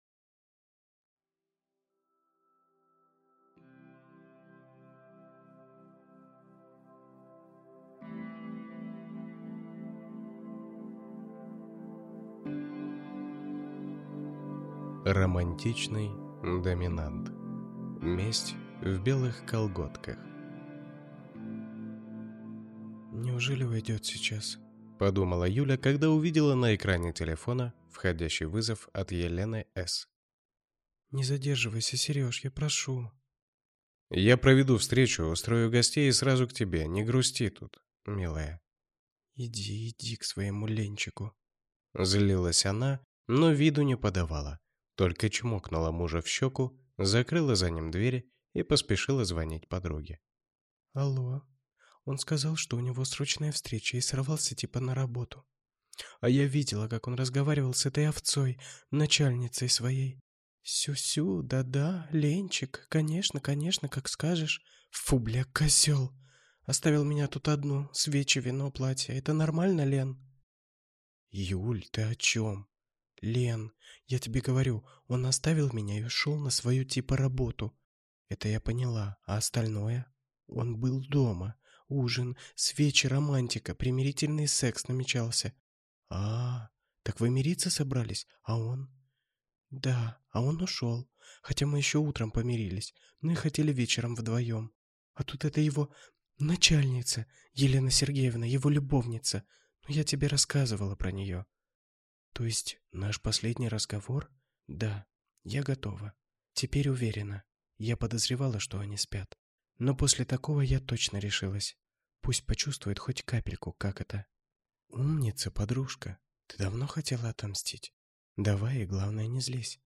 Аудиокнига Месть в белых колготках | Библиотека аудиокниг